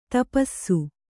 ♪ tapassu